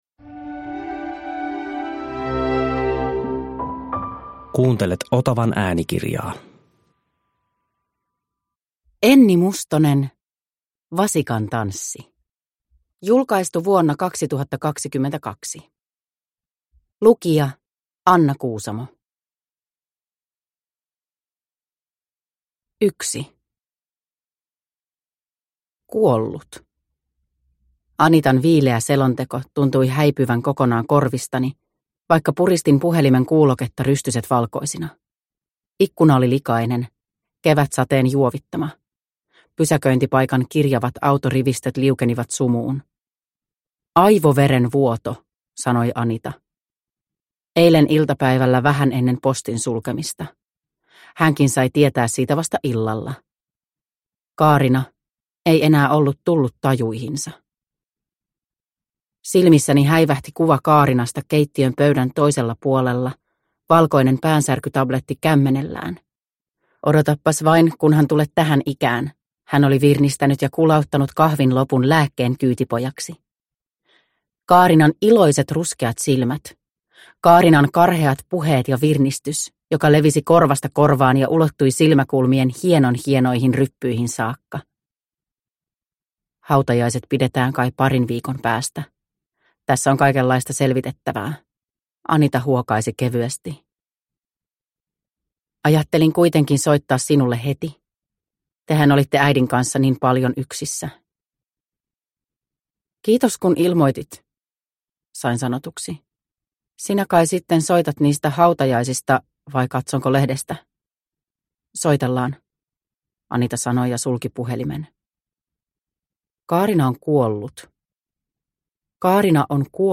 Vasikantanssi – Ljudbok – Laddas ner